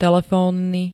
Zvukové nahrávky niektorých slov
qzrg-telefonny.ogg